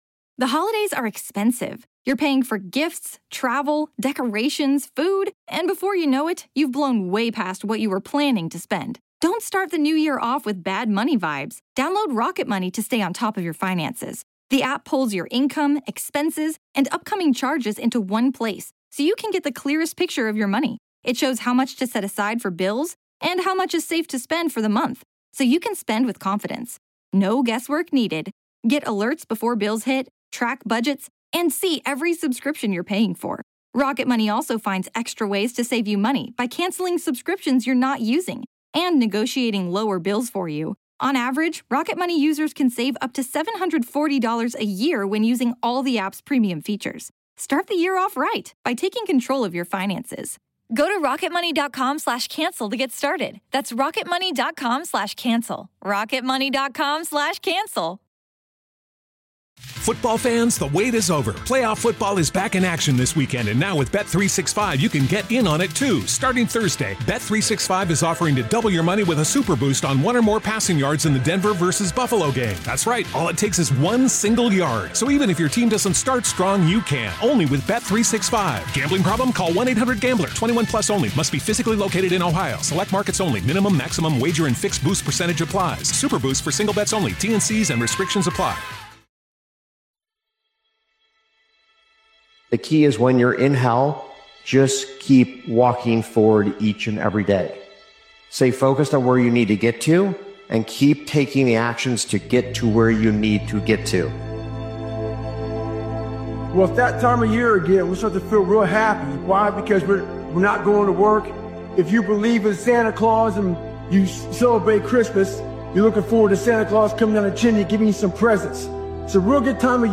This powerful motivational speeches compilation is built for the moments when life hits the hardest—when everything feels heavy, chaotic, and unfair.